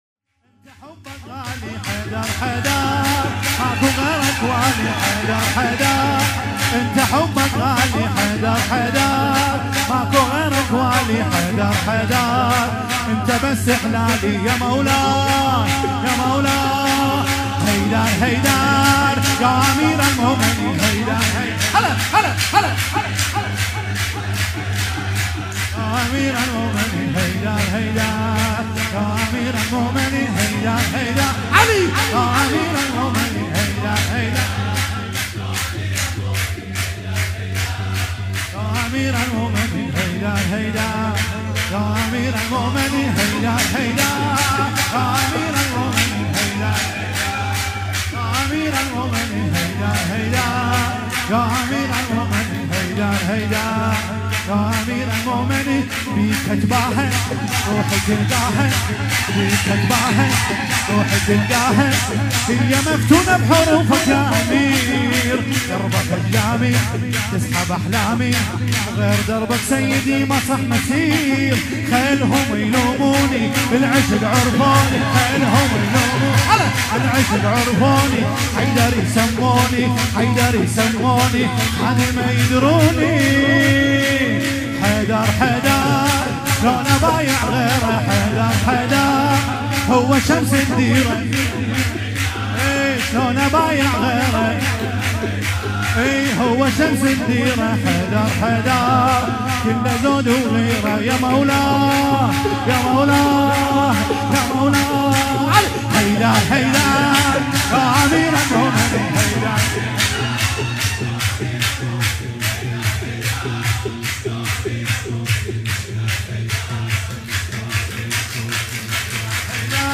مولودی- حیدر حیدر